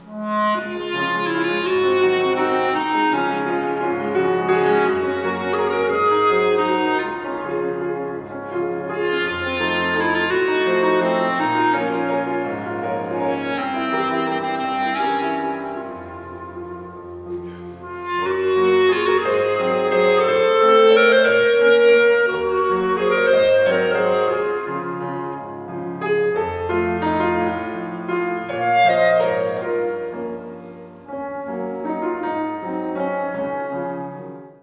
Partie de piano et de clarinette